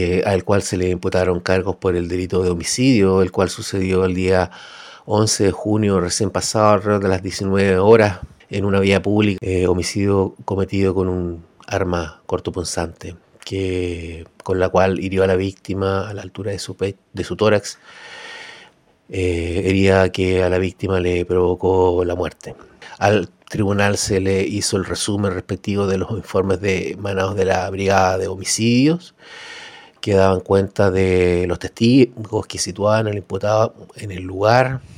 El fiscal Jorge Münzenmayer detalló que en la audiencia se presentaron los informes emanados de la Brigada de Homicidios —a cargo de la indagatoria—, la declaración de testigos presenciales que situaron al imputado en el lugar del crimen y los antecedentes de la autopsia.
fiscal-internacion-homicidio-.mp3